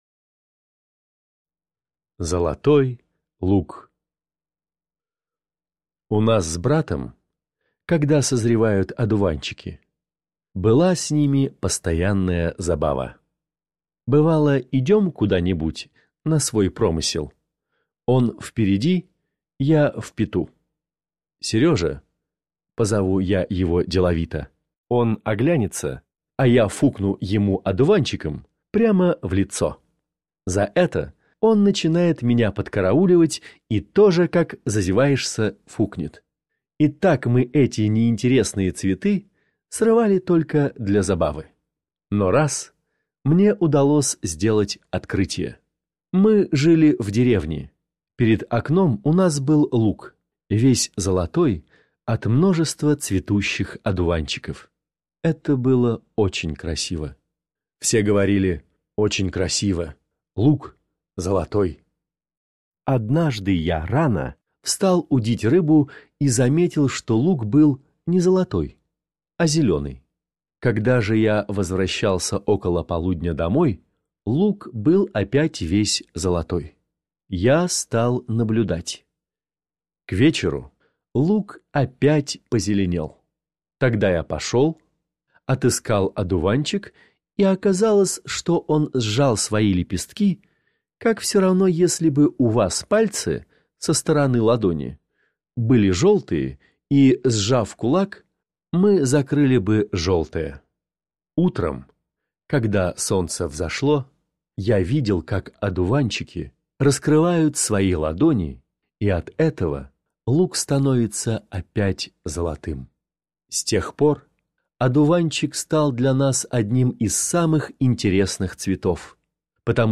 Для того, чтобы лучше понять произведение, его язык, послушаем прочтение текста в исполнении чтеца-профессионала.